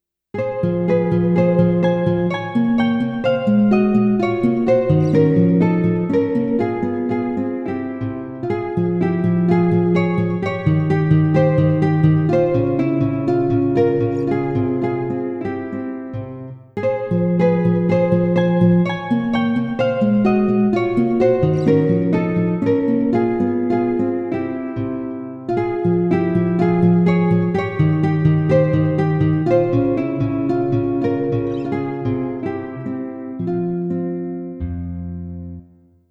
着信メロディ
尚、着メロの雰囲気を醸すために原曲のピッチを2度近く上げており、長さは30秒程度としています。